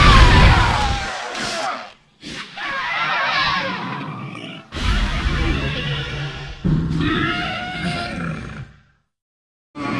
Крики и звуки зомби: